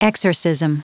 Prononciation du mot exorcism en anglais (fichier audio)
Prononciation du mot : exorcism